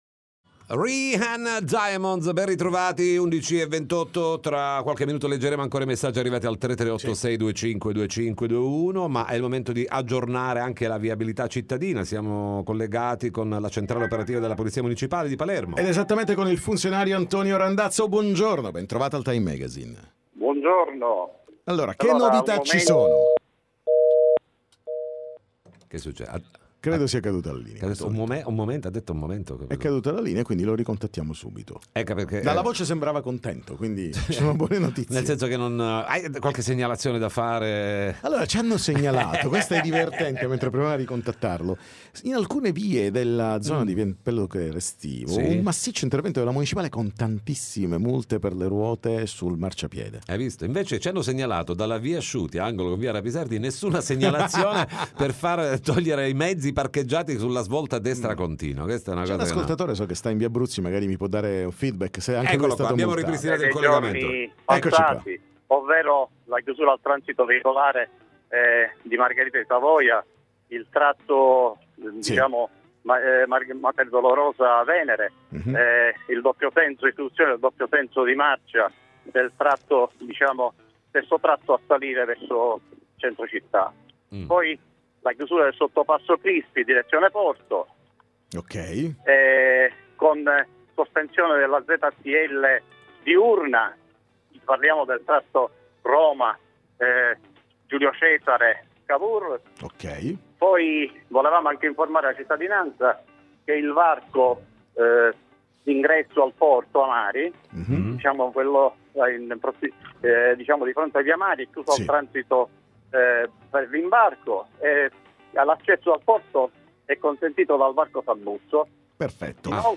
TM Intervista Polizia Municipale